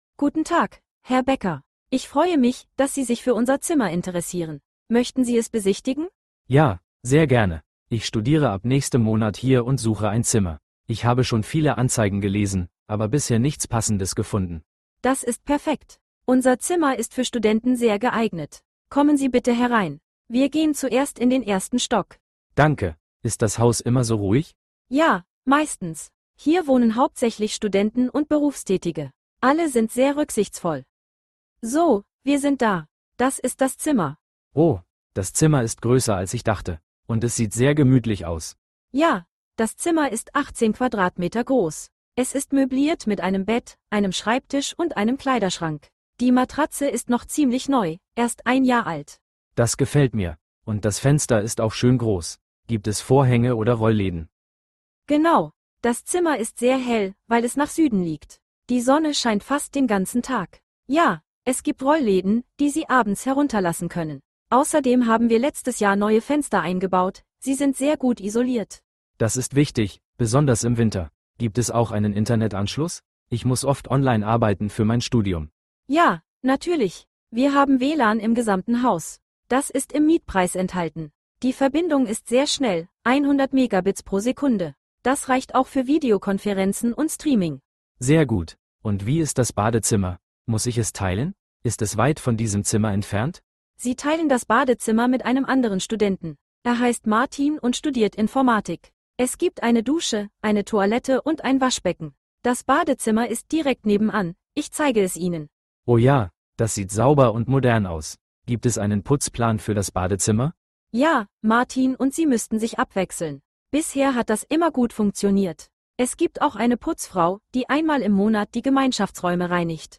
Maklerin